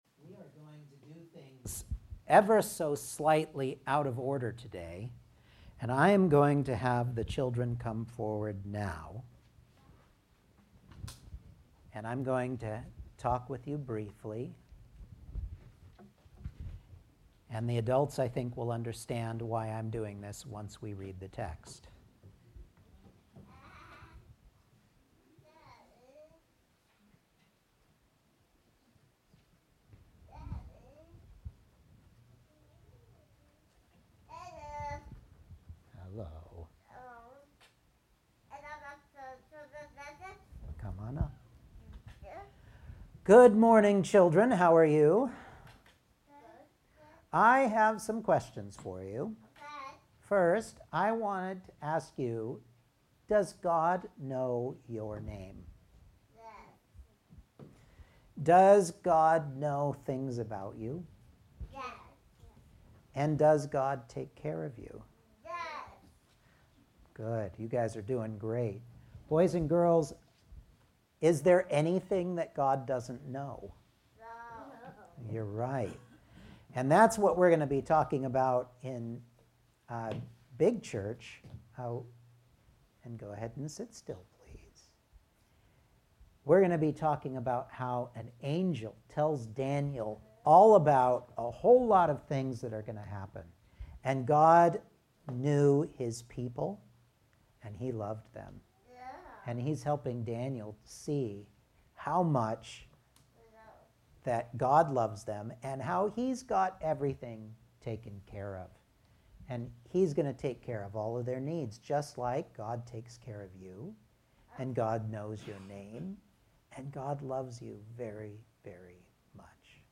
Service Type: Sunday Morning Outline